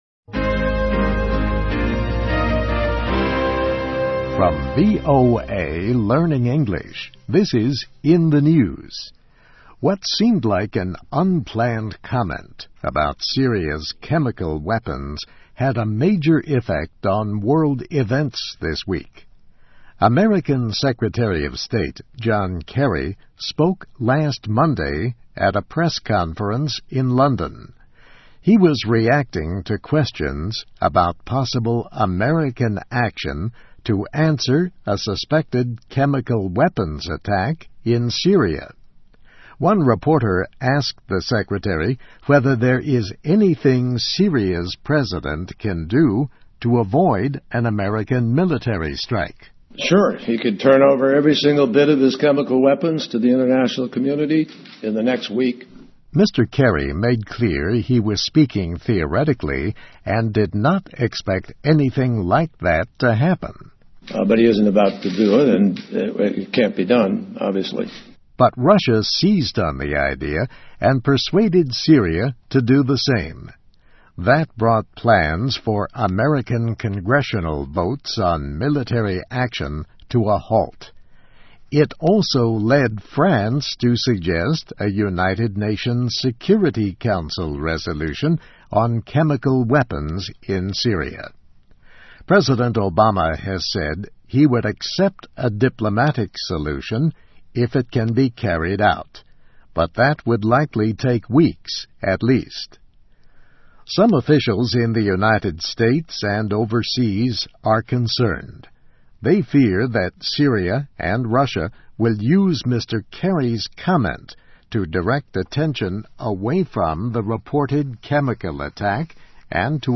VOA时事新闻-克里无意的评论可能会解决叙利亚危机|VOA慢速英语